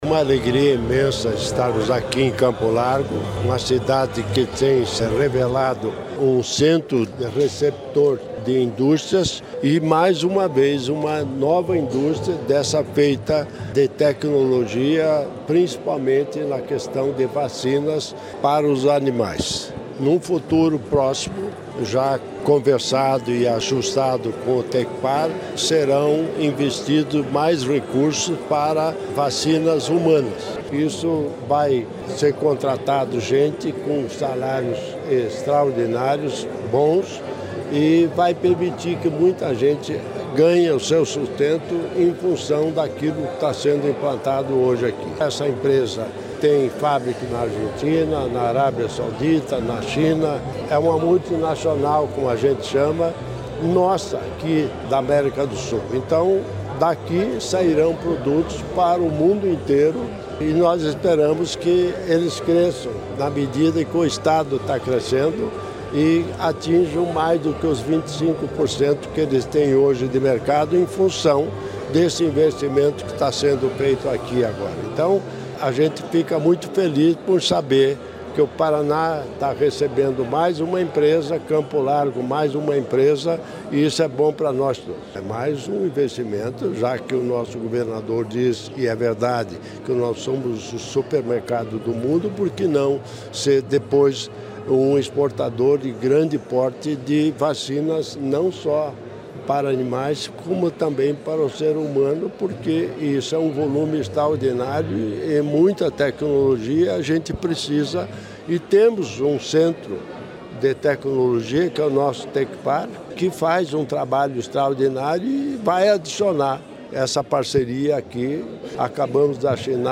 Sonora do governador em exercício Darci Piana sobre o investimento de R$ 100 milhões em indústria veterinária em Campo Largo